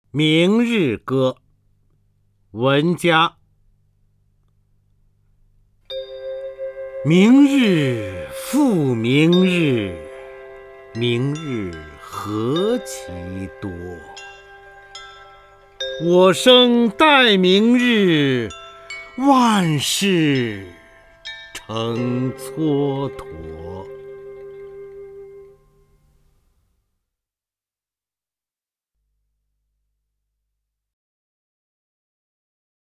方明朗诵：《明日歌》(（明）文嘉) （明）文嘉 名家朗诵欣赏方明 语文PLUS
（明）文嘉 文选 （明）文嘉： 方明朗诵：《明日歌》(（明）文嘉) / 名家朗诵欣赏 方明